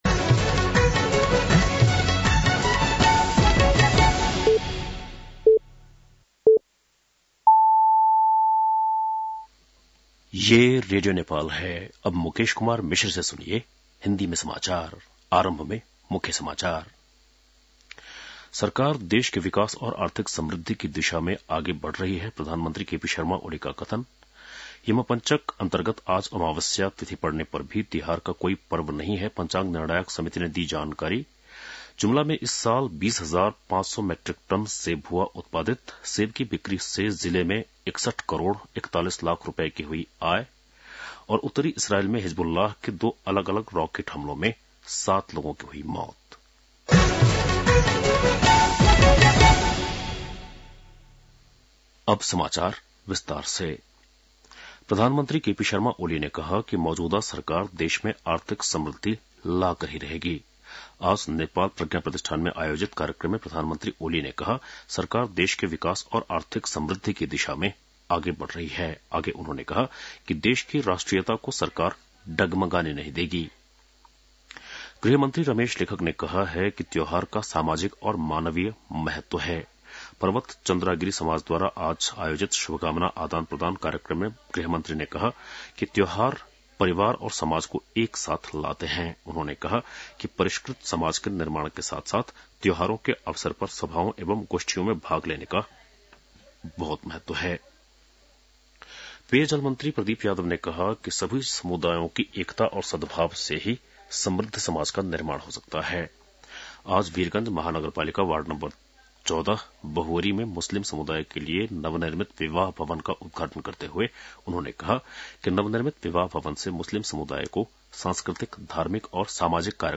बेलुकी १० बजेको हिन्दी समाचार : १७ कार्तिक , २०८१